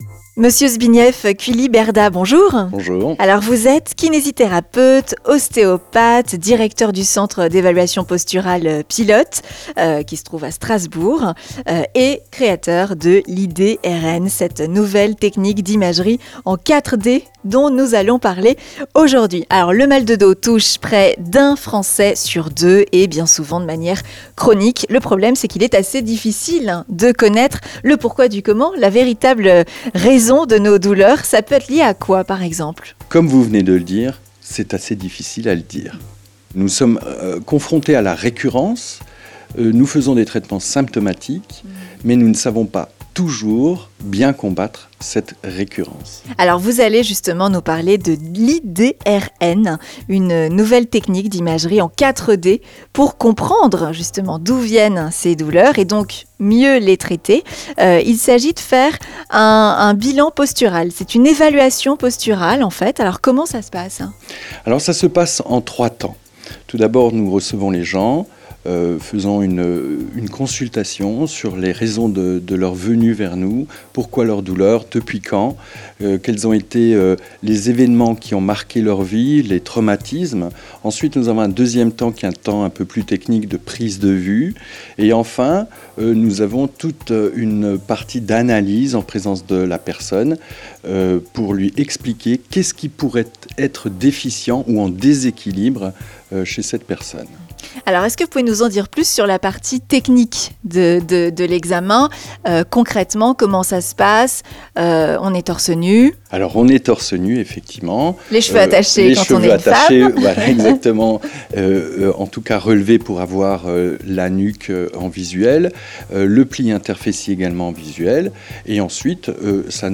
interview-IDRN.mp3